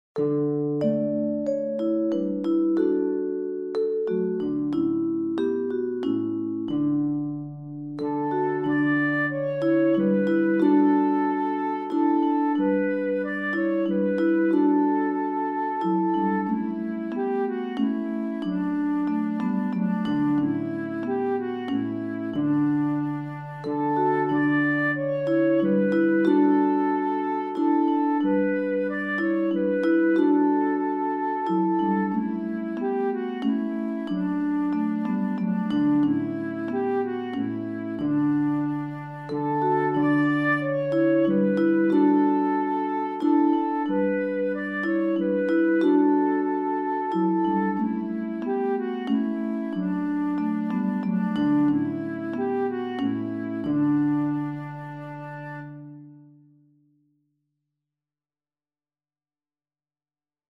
Meerstemmig / of met begeleiding
Langzaam, verheven